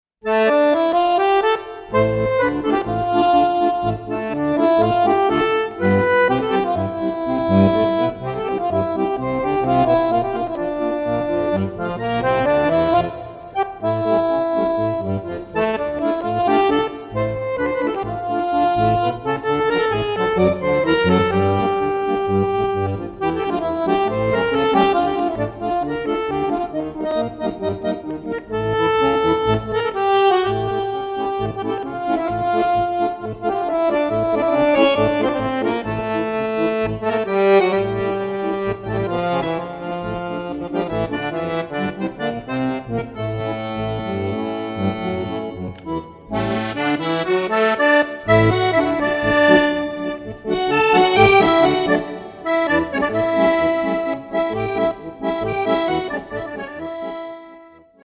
accordion solos
(Solo)